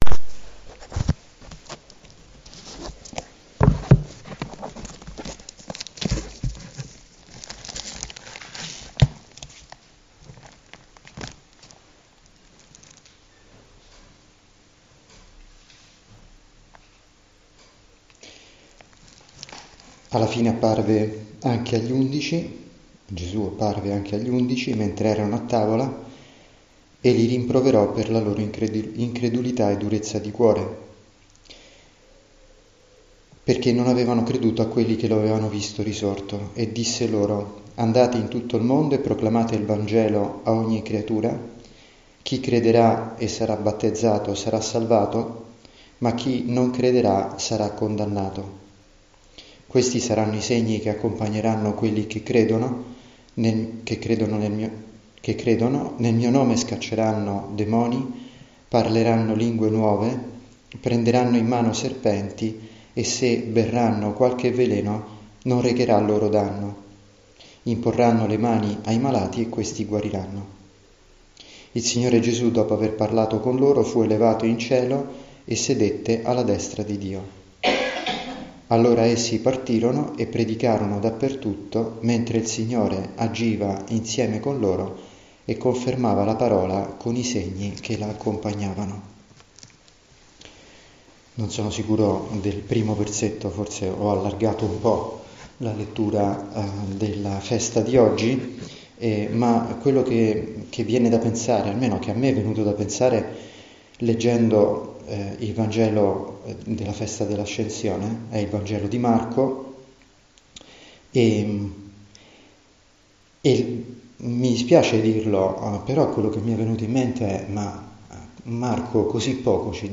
Questa meditazione fa parte di una serie di meditazioni predicate durante degli esercizi spirituali. Ho provato a coniugare i temi abituali con alcuni dei meravigliosi spunti della esortazione apostolica Gaudete et exsultate